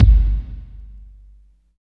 29.10 KICK.wav